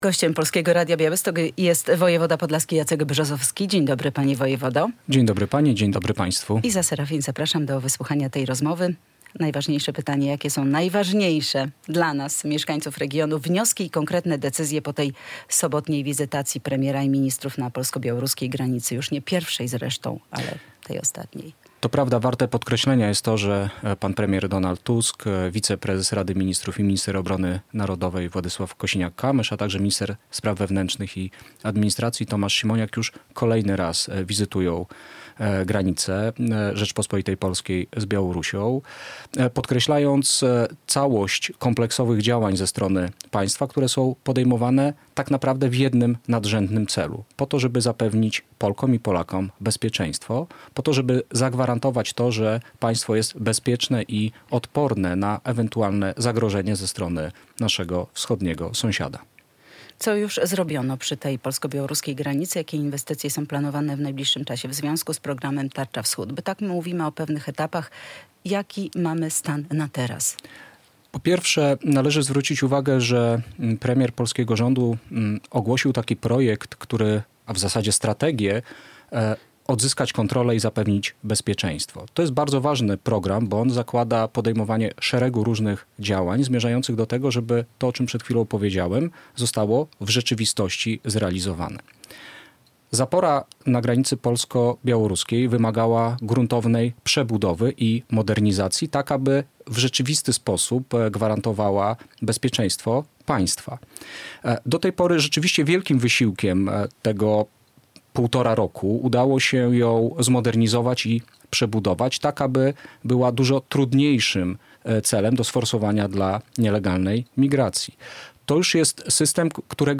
Radio Białystok | Gość | Jacek Brzozowski - wojewoda podlaski
To jest bardzo poważny konkret i bardzo poważne zobowiązanie państwa i jego instytucji do tego, żeby obywatele mieli poczucie bezpieczeństwa - mówił w Polskim Radiu Białystok wojewoda podlaski Jacek Brzozowski.